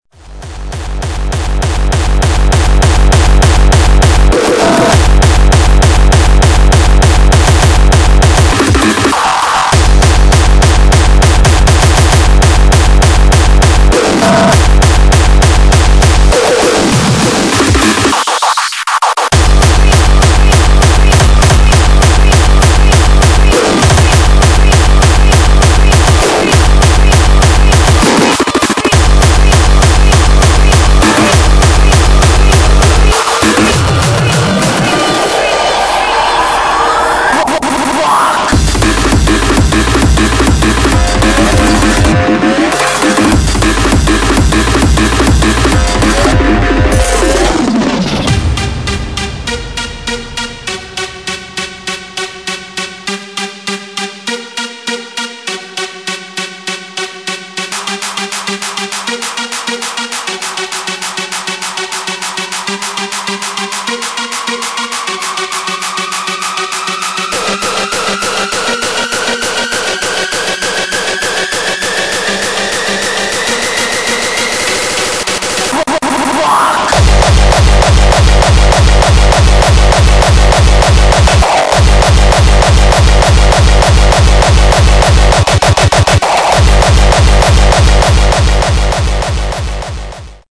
[ HARDCORE ]